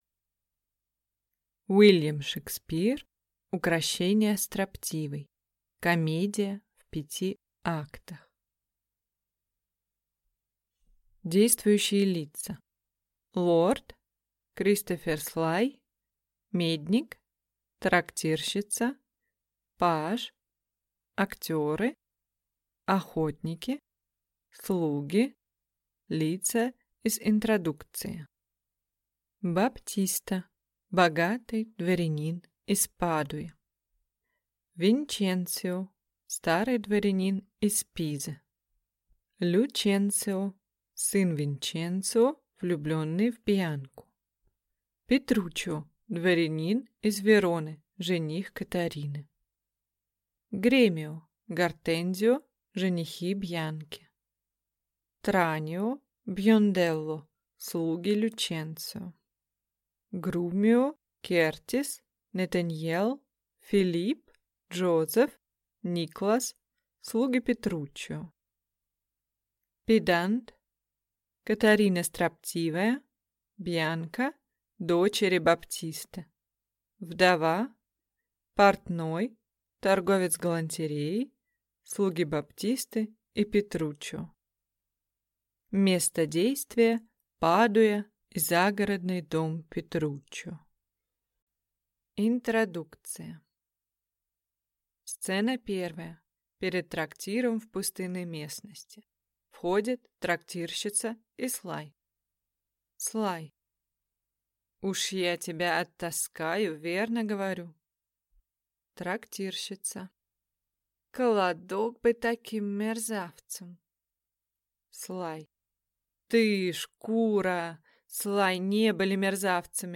Аудиокнига Укрощение строптивой | Библиотека аудиокниг